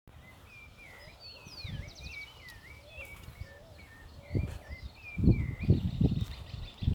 Rufous-bellied Thrush (Turdus rufiventris)
Detailed location: Alrededores de la Ciudad
Condition: Wild
Certainty: Recorded vocal
Audio-Zorzal-Colorado.mp3